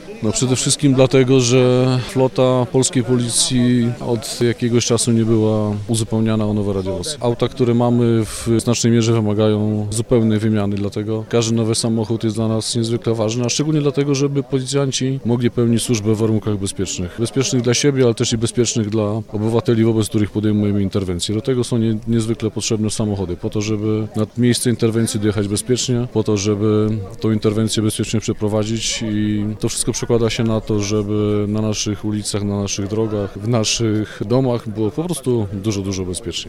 Dlaczego to tak ważny projekt? Mówi nadinsp. Paweł Półtorzycki, komendant wojewódzki policji.